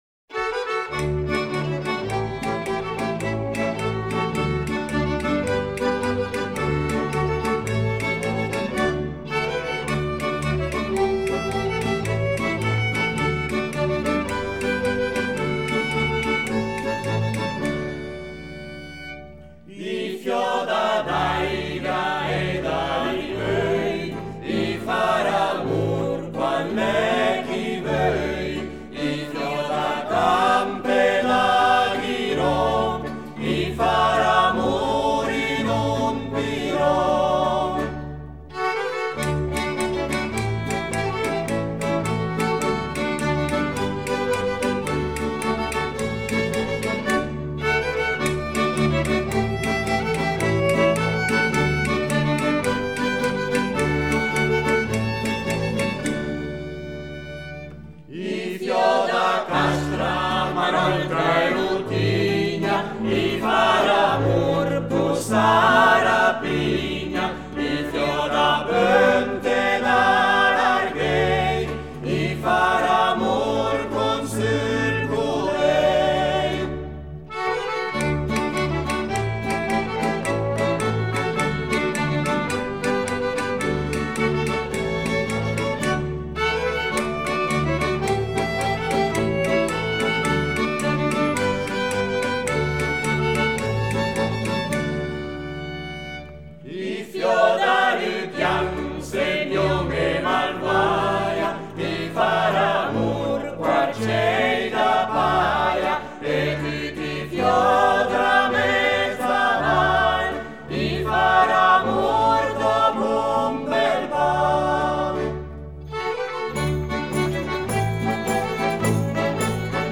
Ticino: Genuine Folk Music from Southern Switzerland
Vox Blenii